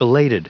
Prononciation du mot belated en anglais (fichier audio)
Prononciation du mot : belated